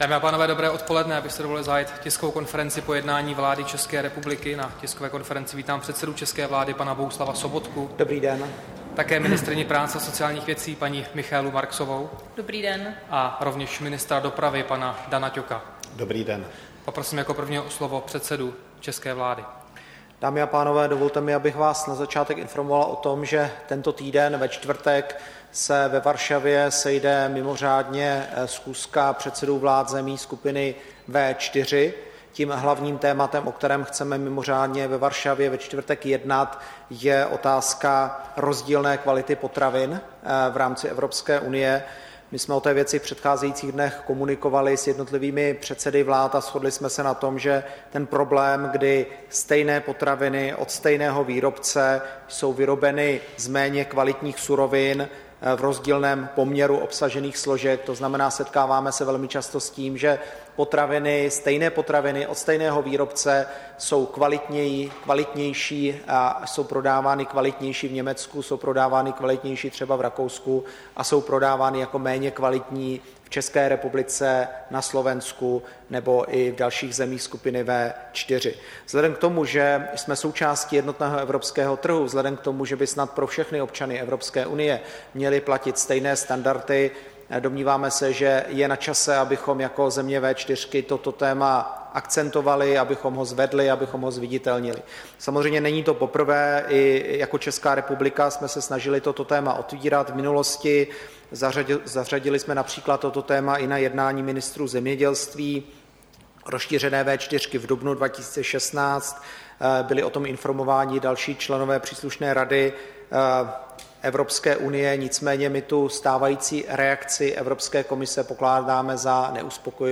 Tisková konference po jednání vlády, 27. února 2017